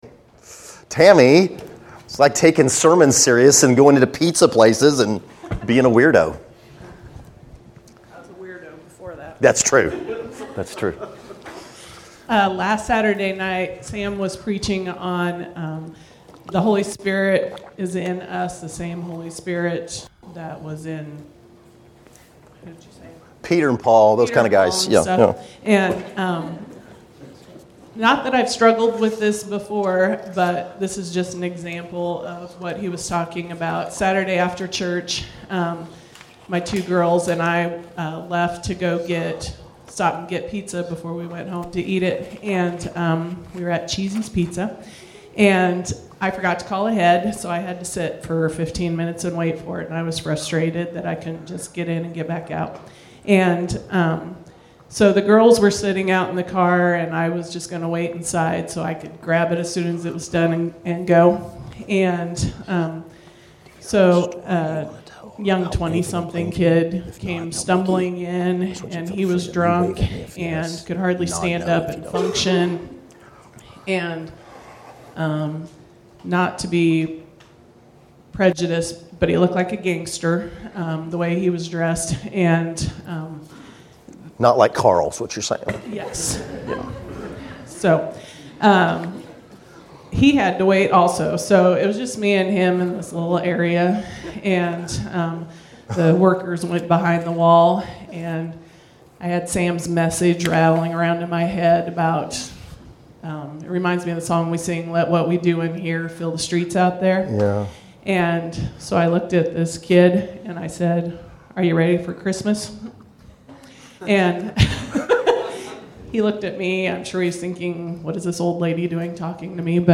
Category: Testimonies      |      Location: Wichita